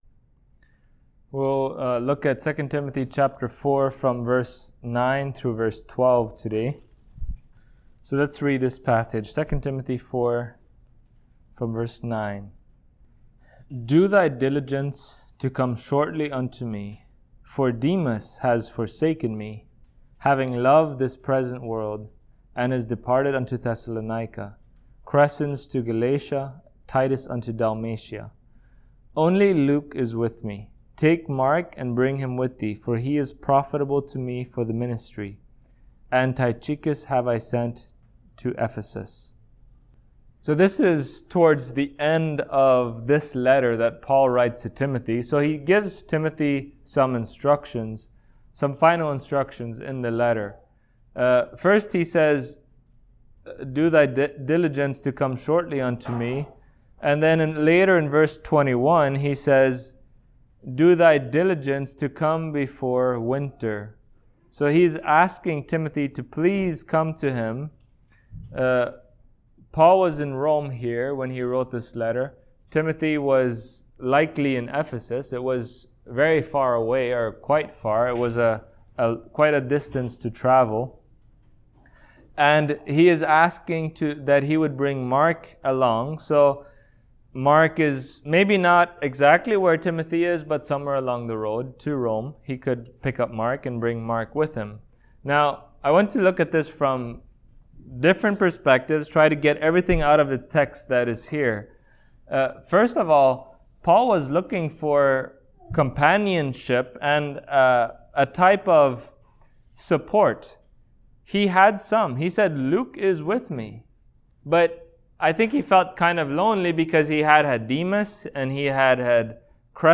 Service Type: Sunday Morning Topics: Brotherhood , Christian Living , Unity